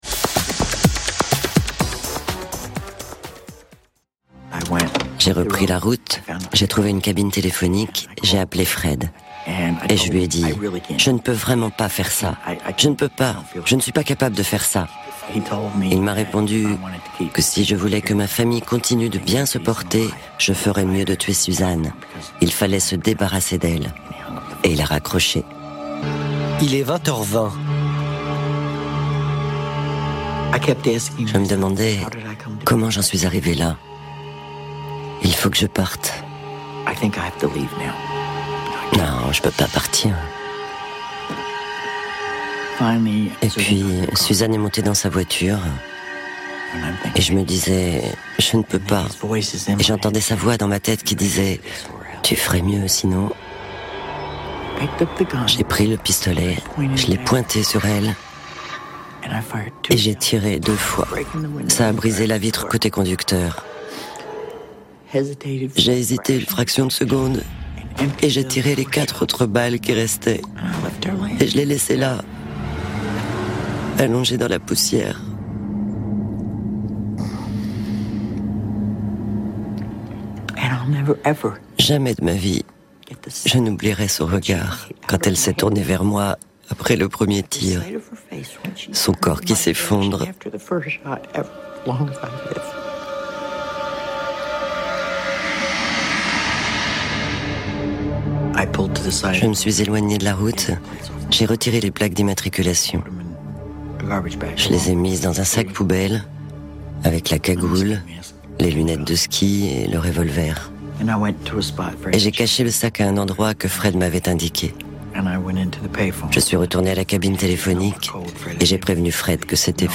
Sprechprobe: eLearning (Muttersprache):
Voice over female